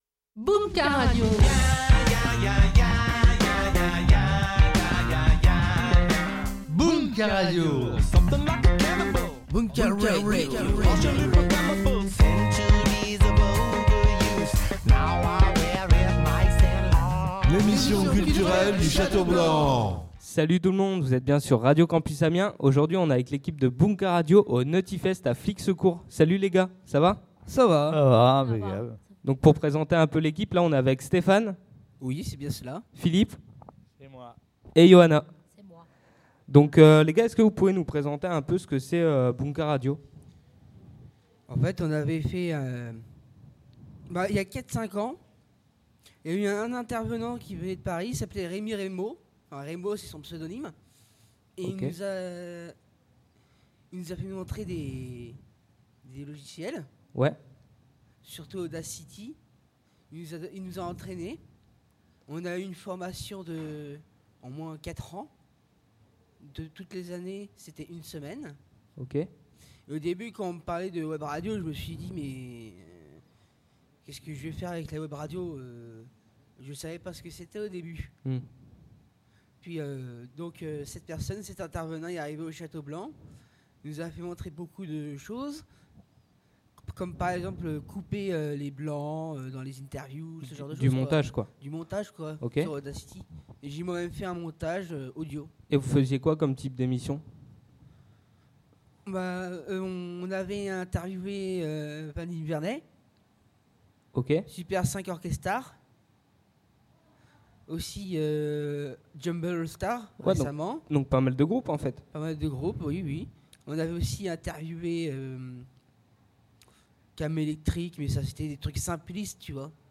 Le samedi 8 Juillet dernier, nous étions avec l’équipe de Bunka Radio en direct du Nutty Fest, un festival reggae / dub organisé par le Château Blanc ADAPEI 80 à Flixecourt !